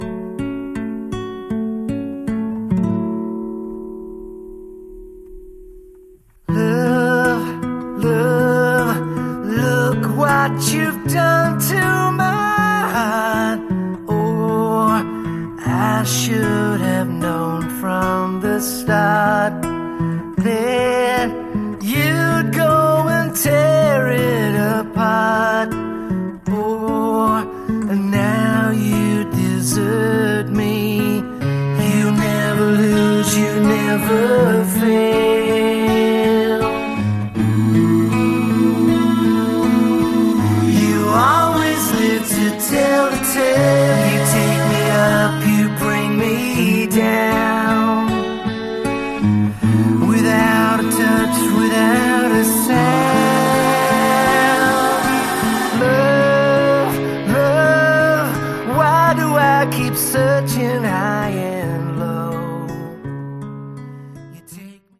Category: Hard Rock / Melodic Rock